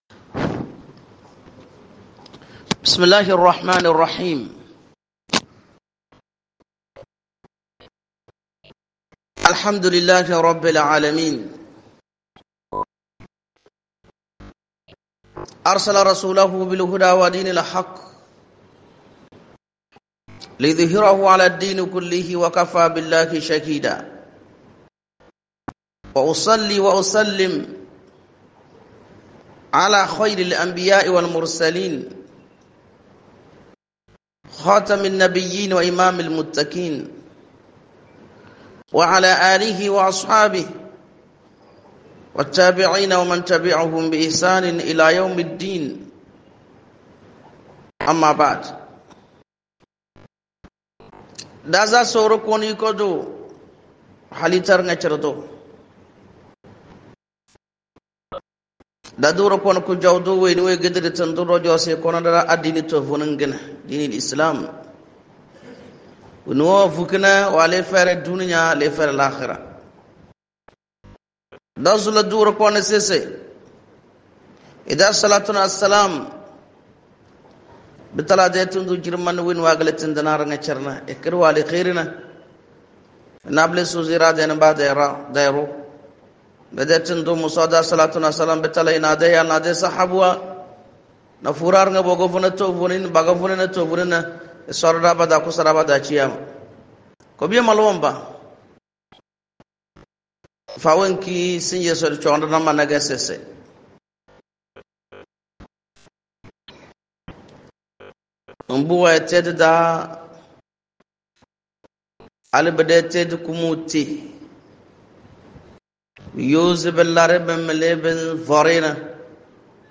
PRECHE